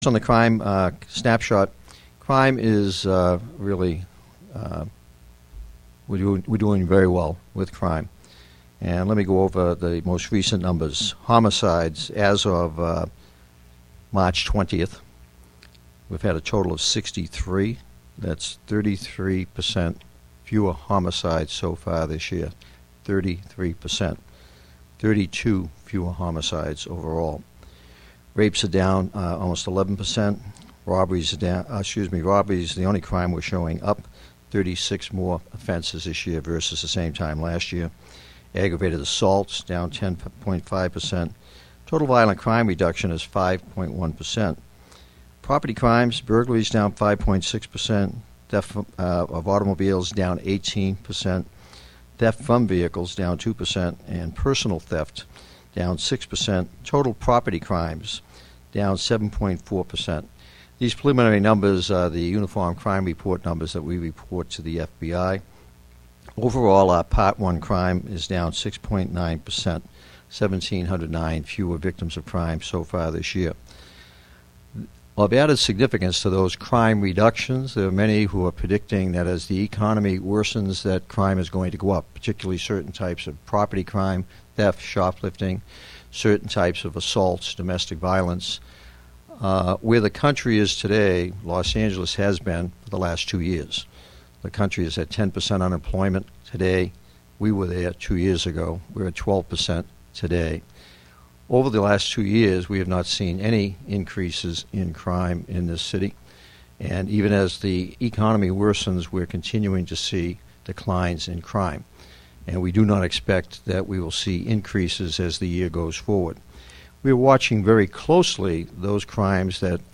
Chief Bratton Hosts Media Availability News Conference for March Topics Include Honors for Heroism and Chief’s Request for Federal Law Enforcement Funds